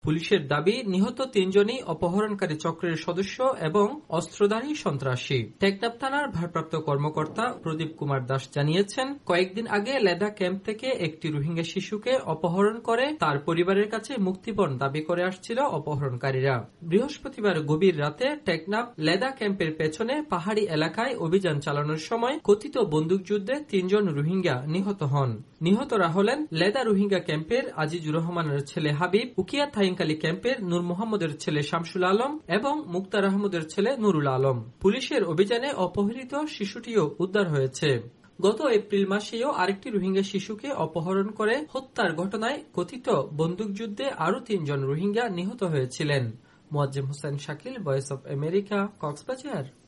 কক্সবাজার থেকে